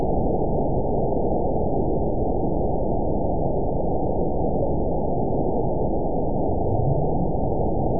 event 921975 date 12/24/24 time 00:15:47 GMT (11 months, 1 week ago) score 9.22 location TSS-AB02 detected by nrw target species NRW annotations +NRW Spectrogram: Frequency (kHz) vs. Time (s) audio not available .wav